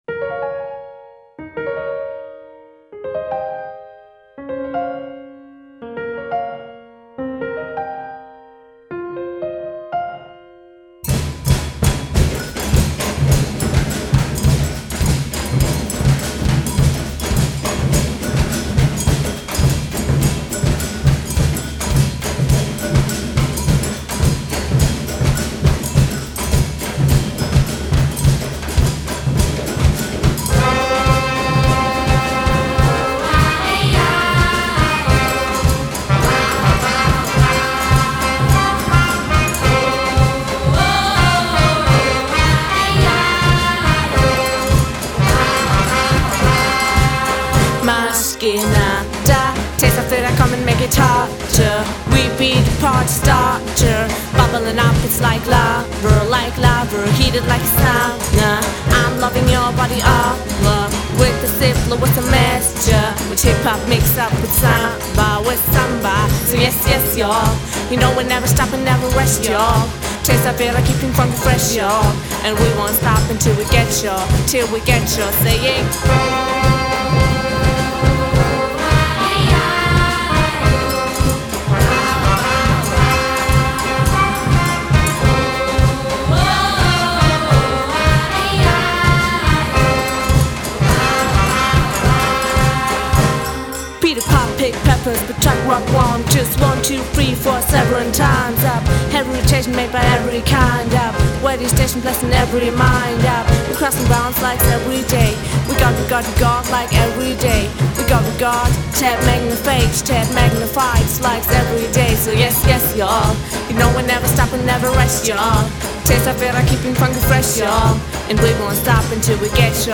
Funk – Samba – Hip Hop - Reggae